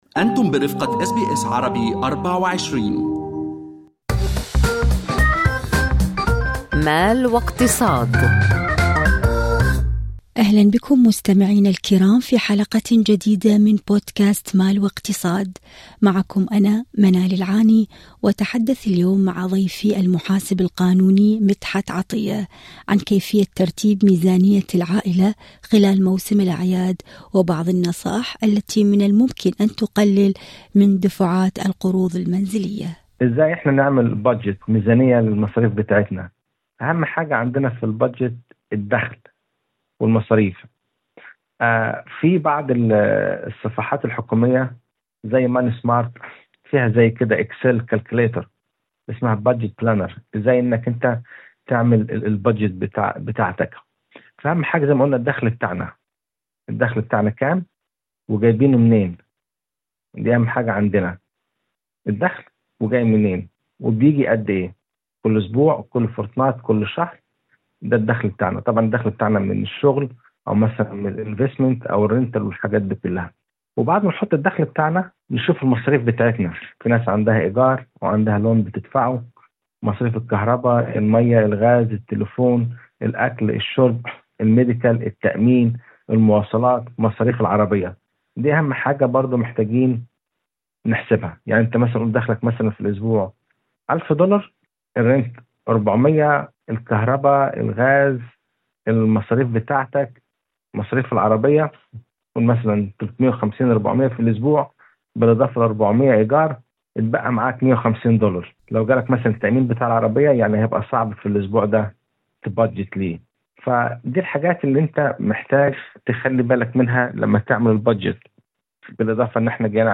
للتنويه فقط: هذا اللقاء يقدم معلومات عامة فقط، لمزيد من التفاصيل عن حالات خاصة عليكم بإستشارة خبير اقتصادي أو محاسب قانوني خاص بكم. فما هي الخطوات الصحيحة والعملية لترتيب ميزانية العائلة؟